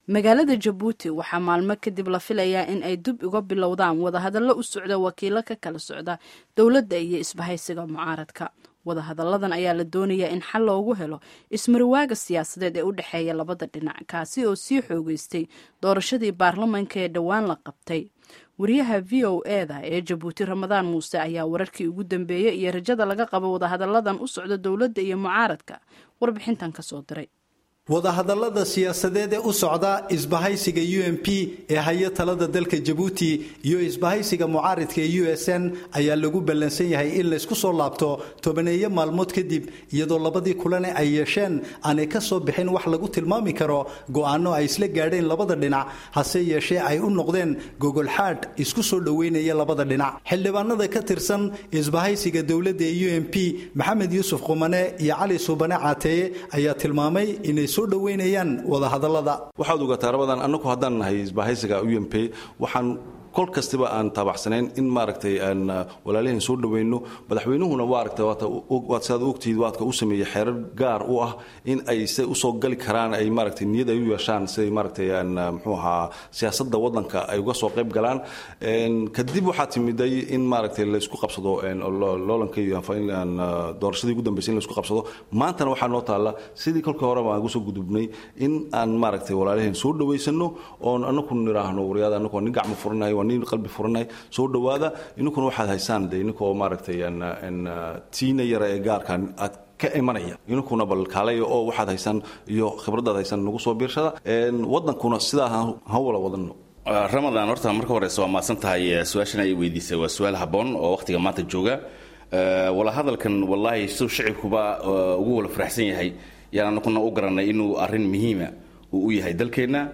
Dhageyso Warbixinta Jabuuti